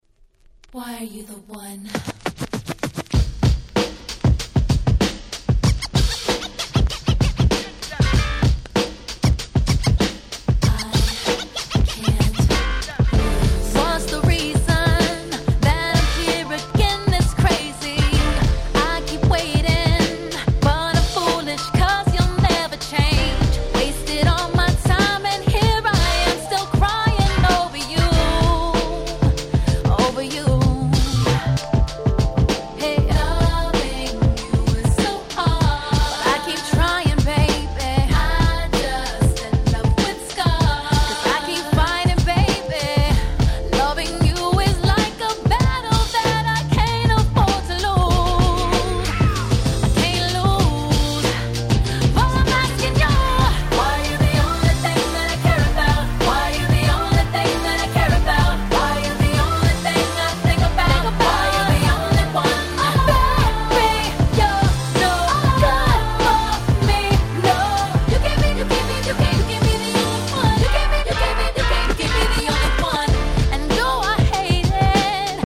09' Smash Hit R&B !!